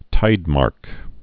(tīdmärk)